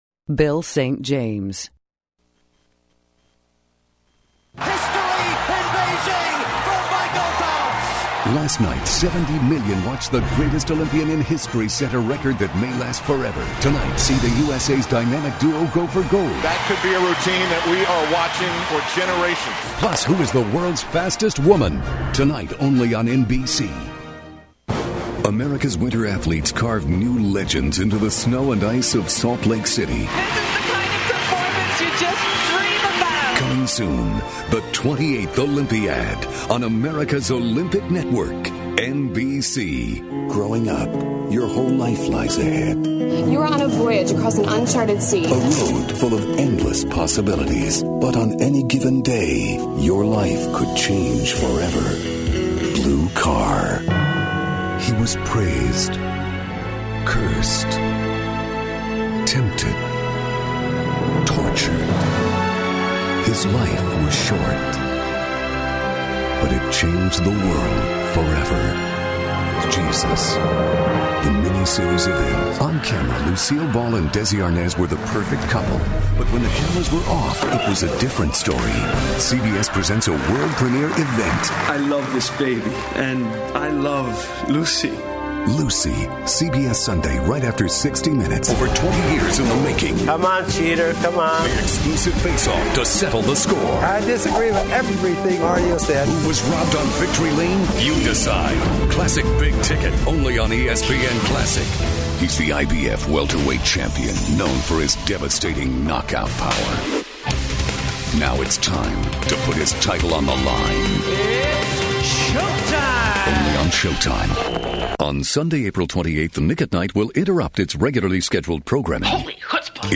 Male VOs